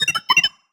sci-fi_driod_robot_emote_beeps_06.wav